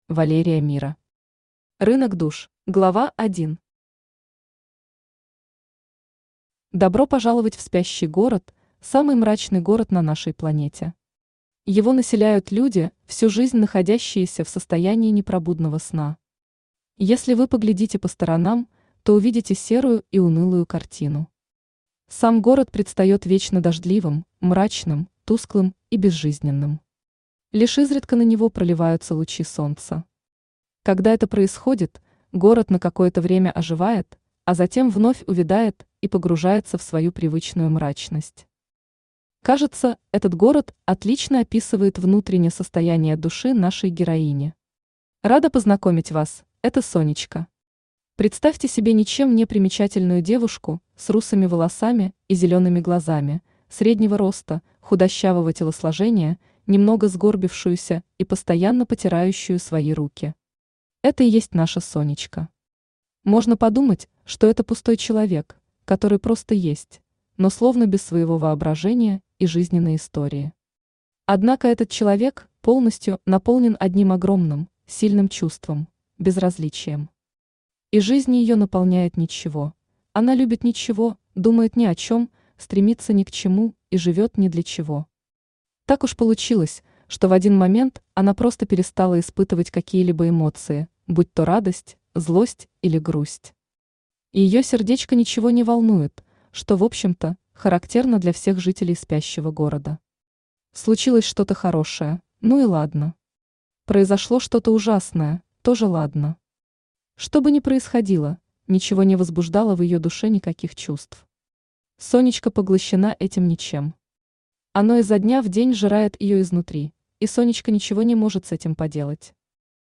Aудиокнига Рынок душ Автор Валерия Мира Читает аудиокнигу Авточтец ЛитРес.